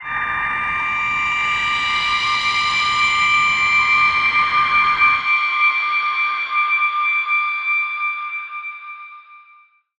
G_Crystal-D7-f.wav